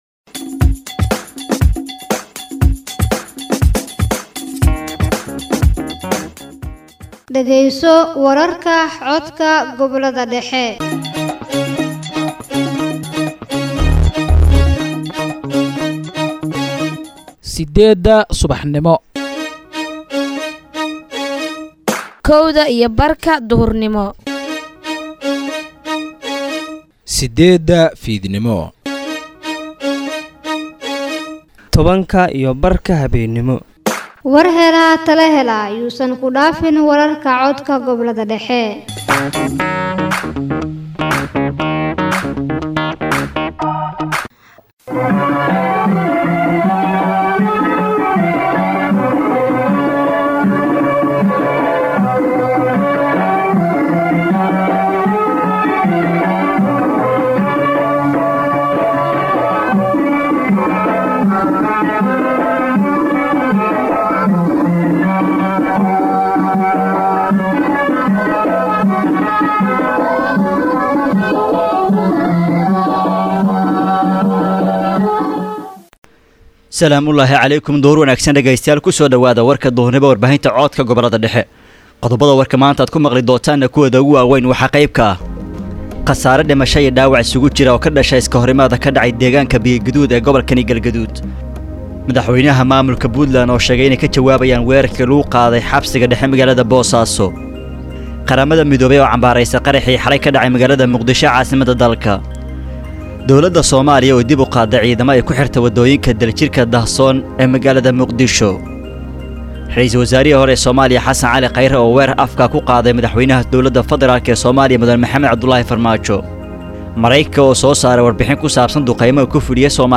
halkaan ka dhagayso warha dugur ee idaacaddda codka gobolada dhexe.